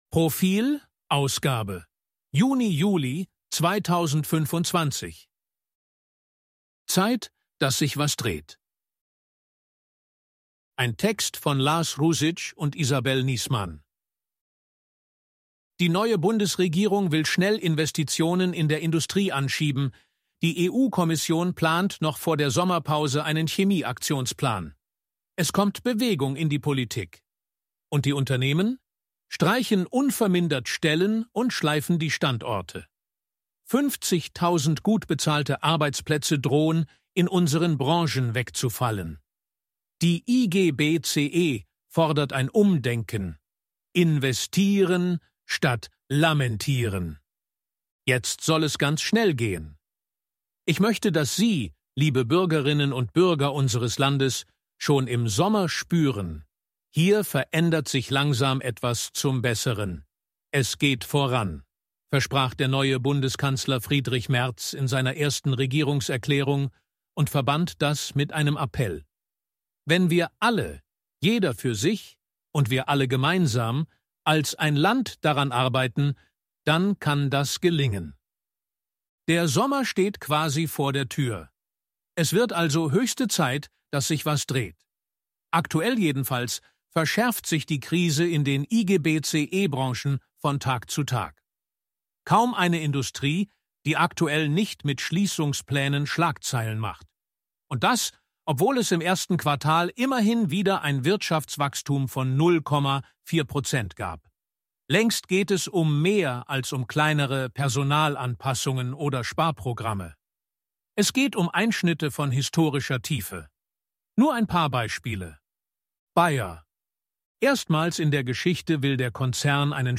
253_ElevenLabs_KI_Stimme_Mann_HG-Story_64.mp3